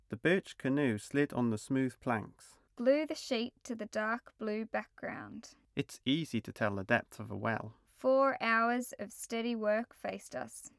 speech.opus